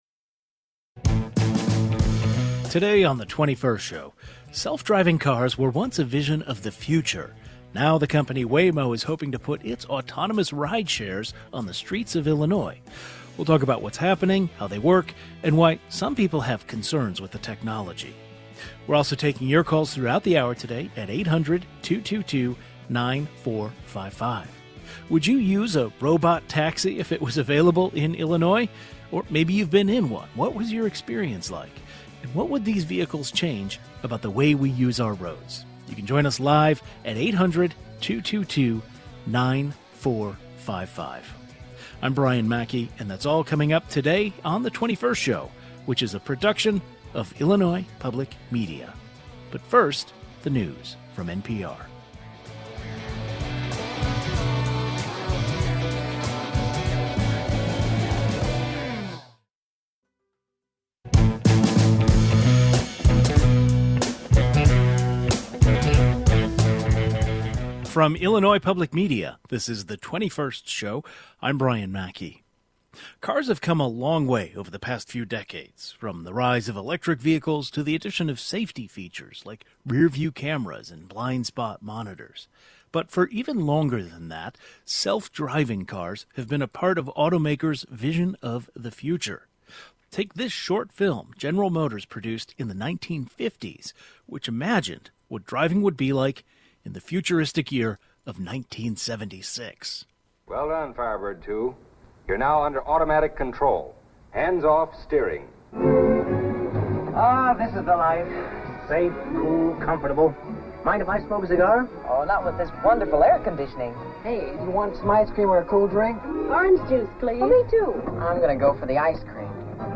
Now, the company Waymo is hoping to put its autonomous rideshares on the streets of Illinois. A panel of experts weigh in on what's happening with self-driving cars in the state, how they work, and why some people have concerns with the technology.